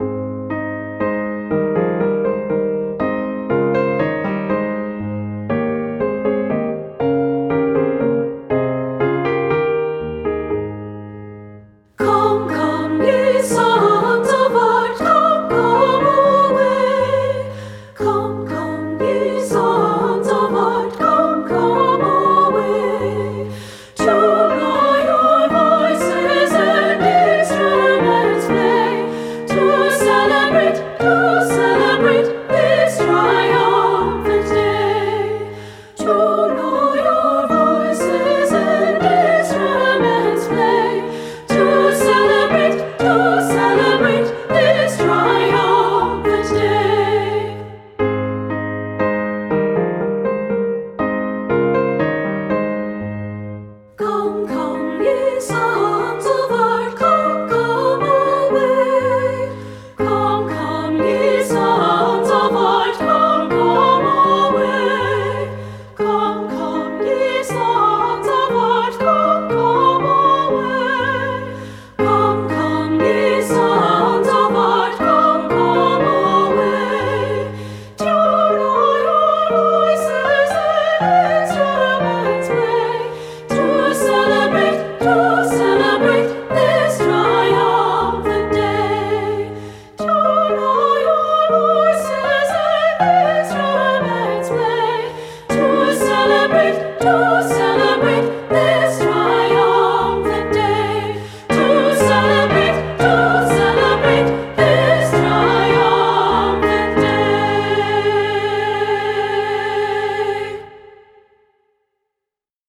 • Soprano
• Alto
• Piano
Studio Recording
Ensemble: Unison and Two-Part Chorus
Key: A major
Tempo: Majestic! (q = 120)
Accompanied: Accompanied Chorus